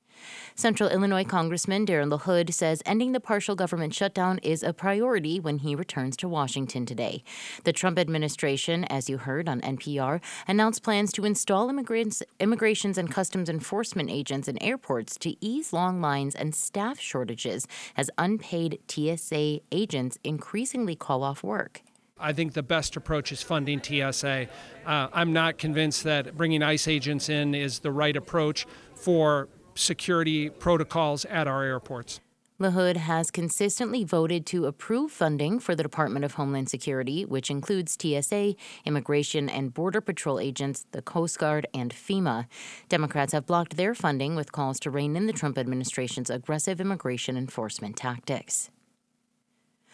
He spoke at a press conference Monday with Bloomington Police Chief Jamal Simington and Mayor Dan Brady.